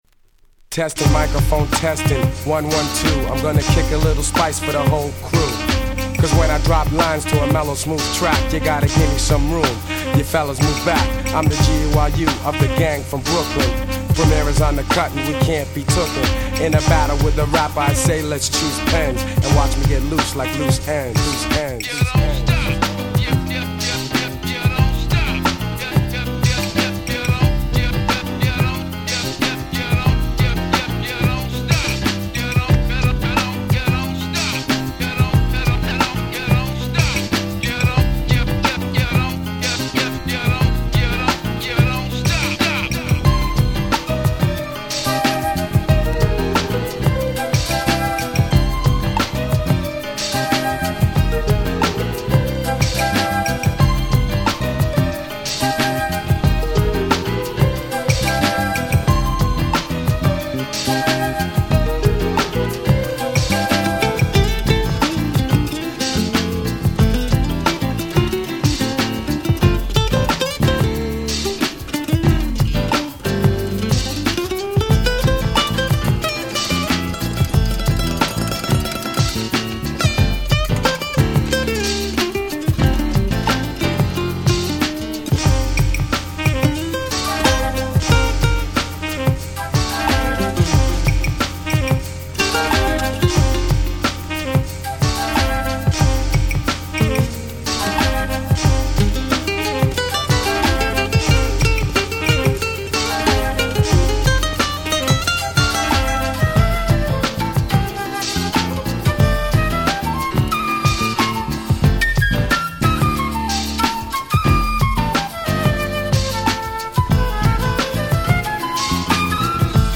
※試聴ファイルは別の盤から録音してあります。